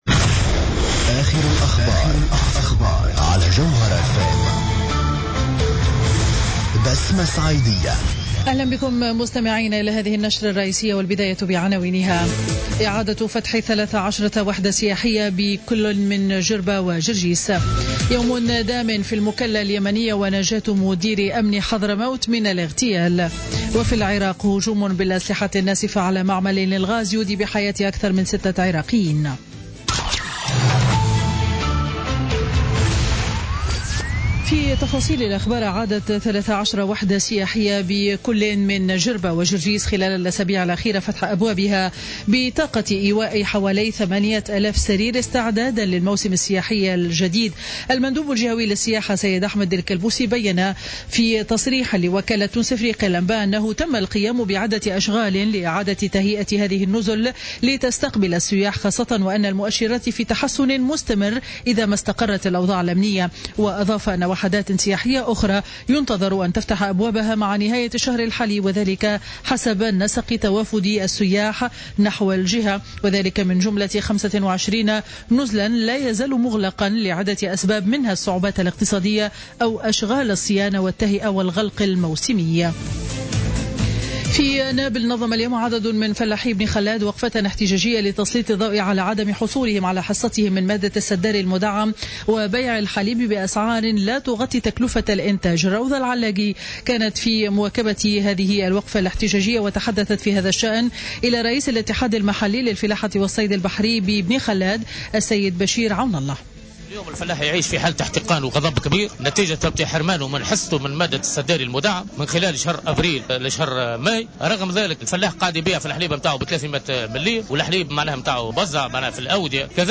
نشرة أخبار منتصف النهار ليوم الأحد 15 ماي 2016